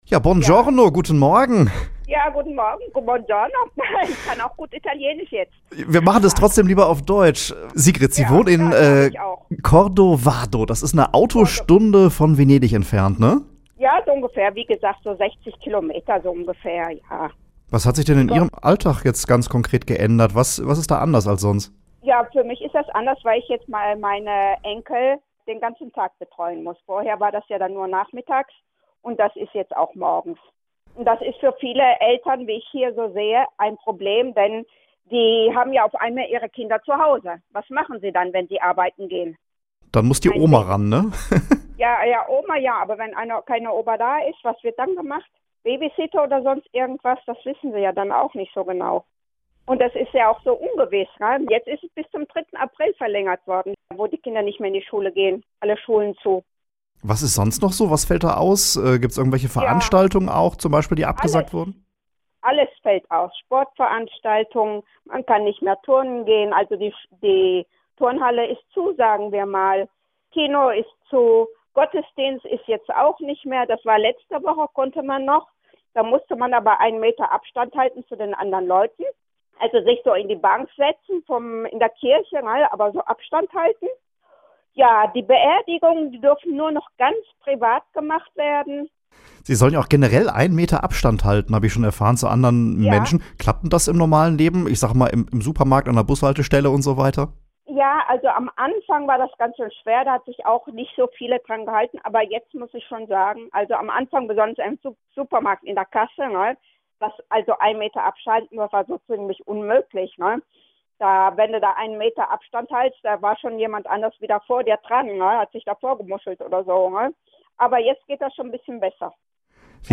Interview zum Corona-Virus in Italien